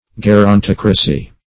Search Result for " gerontocracy" : Wordnet 3.0 NOUN (1) 1. a political system governed by old men ; The Collaborative International Dictionary of English v.0.48: Gerontocracy \Ger`on*toc"ra*cy\, n. [Gr.
gerontocracy.mp3